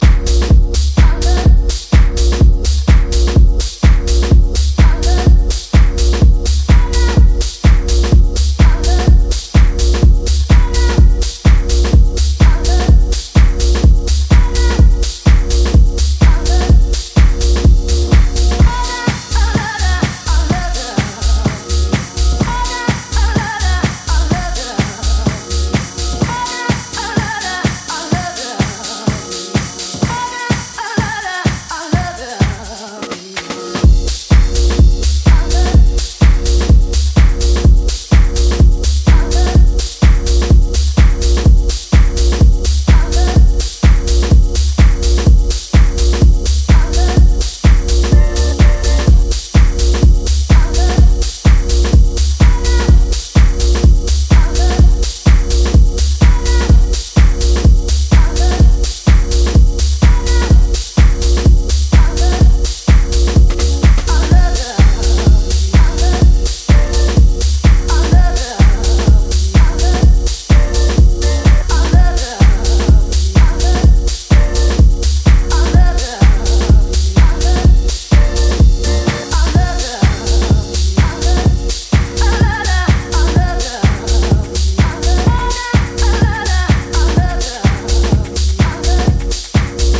ジャンル(スタイル) DEEP HOUSE / DISCO HOUSE